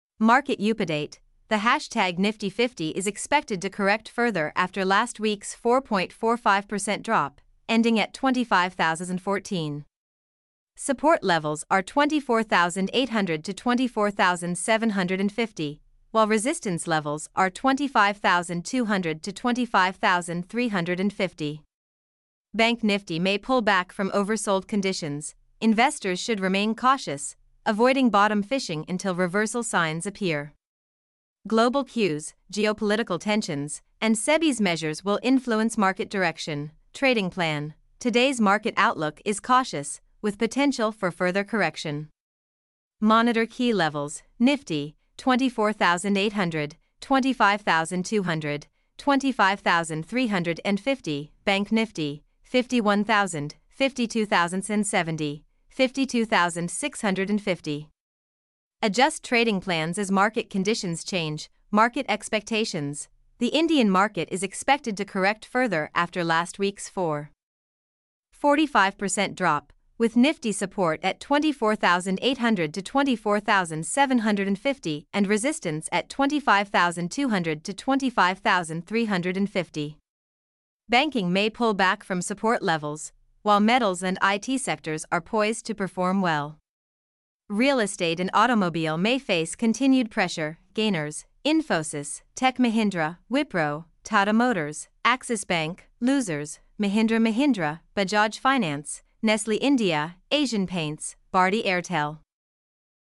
mp3-output-ttsfreedotcom-6.mp3